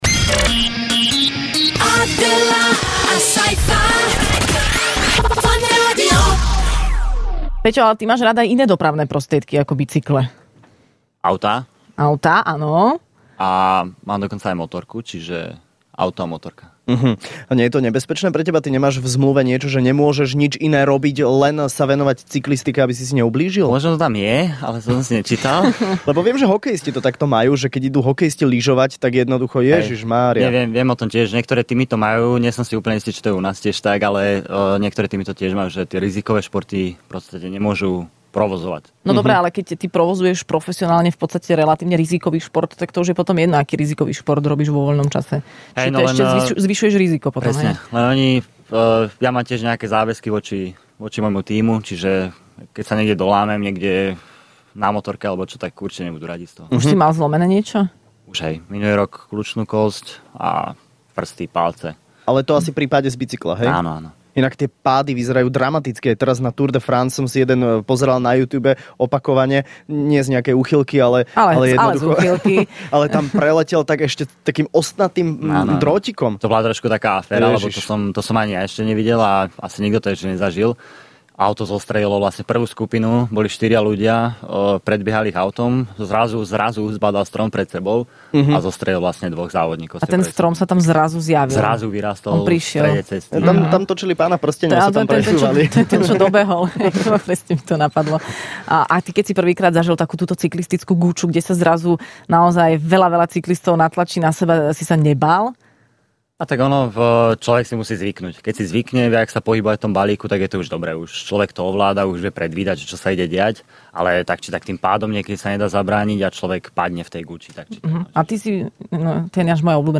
Hosťom v Rannej šou bol cyklista Peter Velits, ktorý sa vrátil z legendárnej Tour de France, kde sa umiestnil na celkovo vynikajúcom 19. mieste.